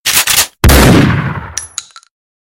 Выстрел Из Дробовика